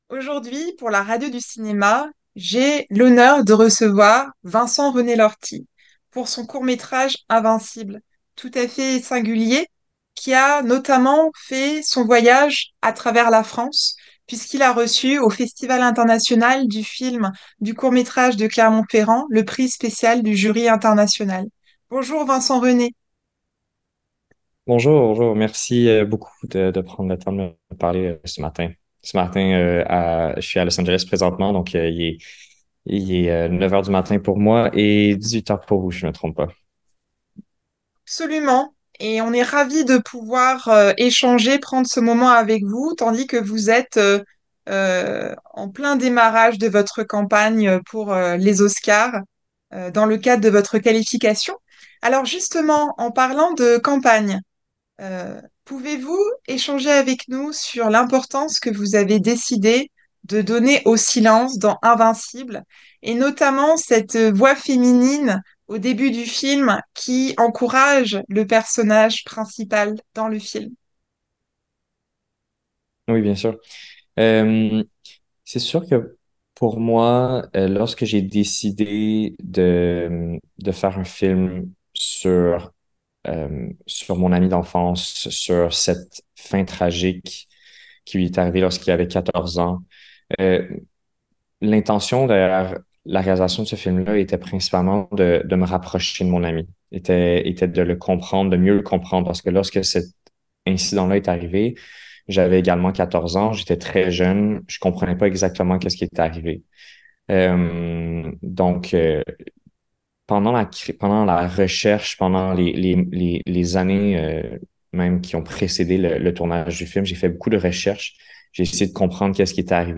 Dans une entrevue pour la radio du cinéma